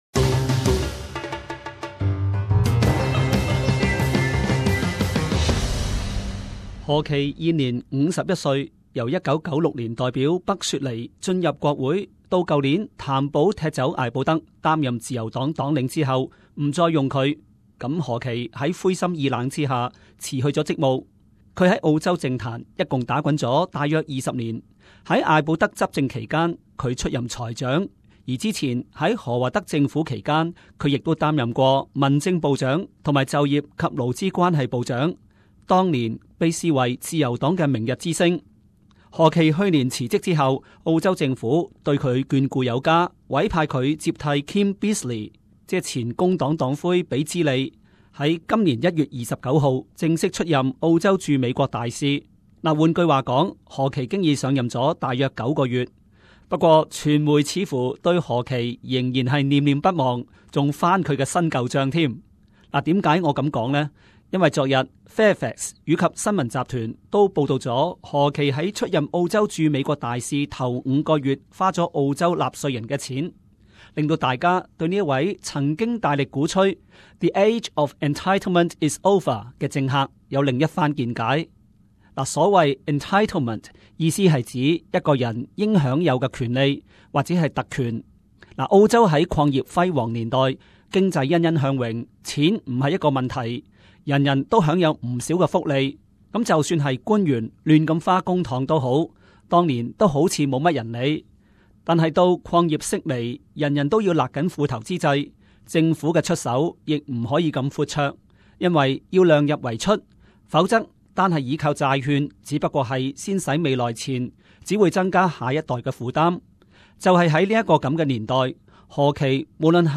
【時事報導】 駐美大使何奇以公帑聘請保姆